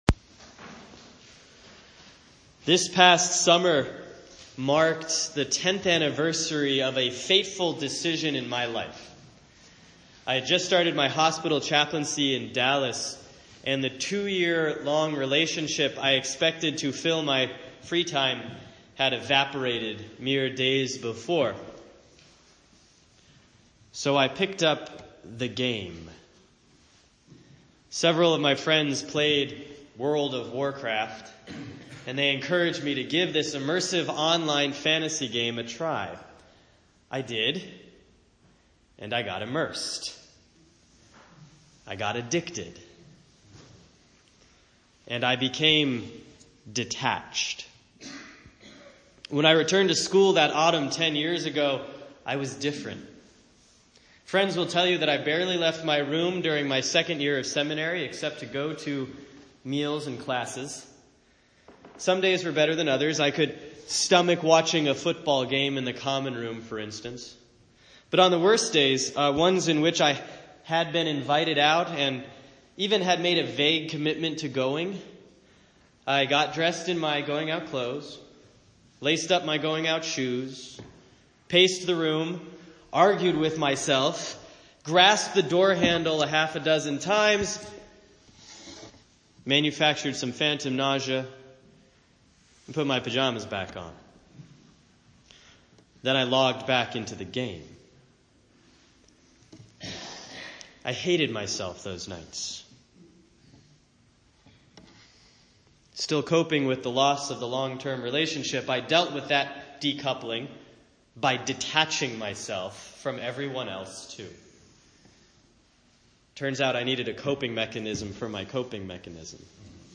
Sermon for Sunday, September 25, 2016 || Proper 21C || Luke 16:19-31